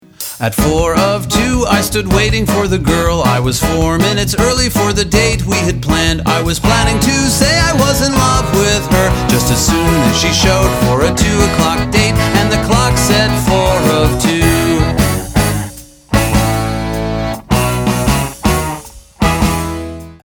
It's a kid's album. It's fun.
And sometimes it's romantic